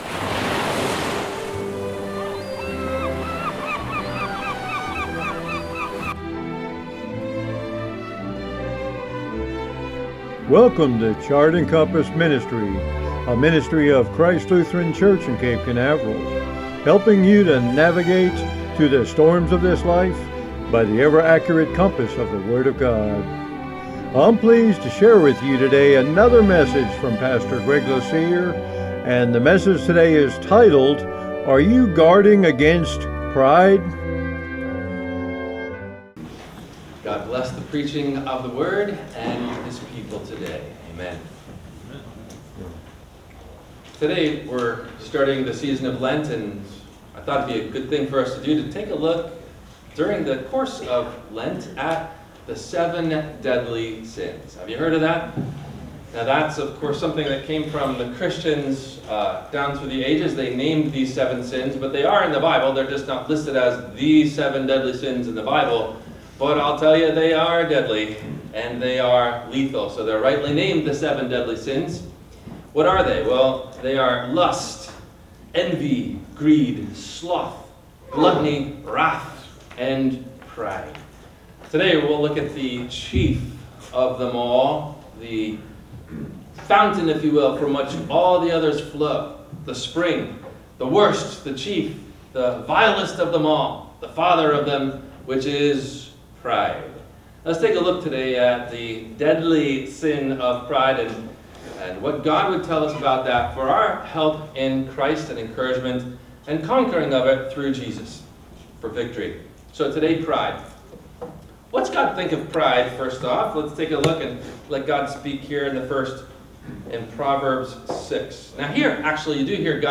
Are You Guarding Against…Pride? – WMIE Radio Sermon – March 24 2025